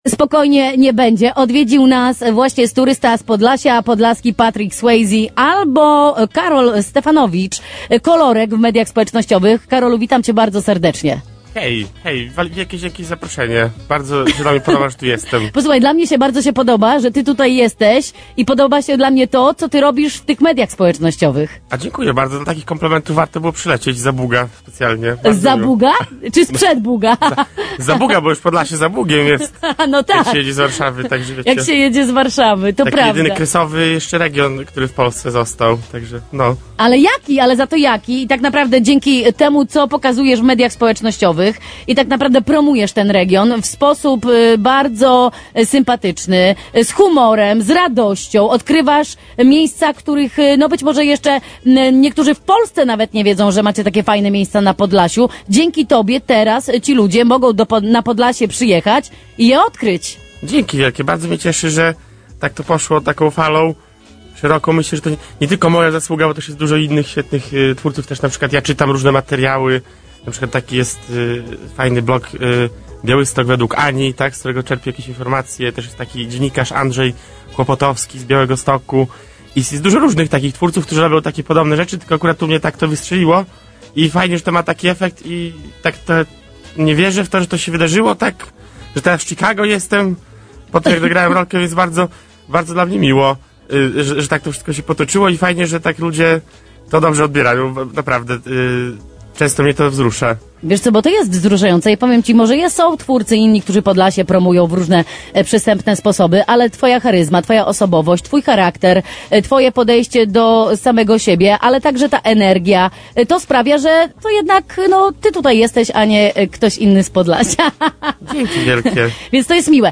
🔥 Było dużo śmiechu, inspiracji i podlaskiego klimatu!